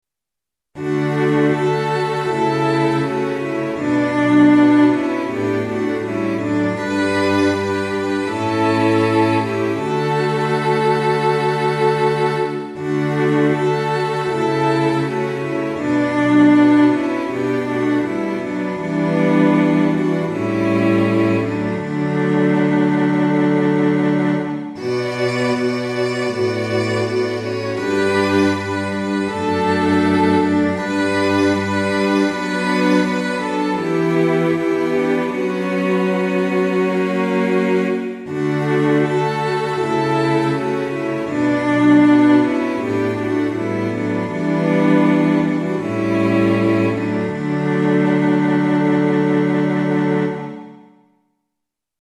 ♪聖歌隊練習補助
Tonality = D
Pitch = 440
Temperament = Equal